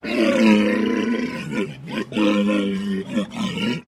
Злость моржа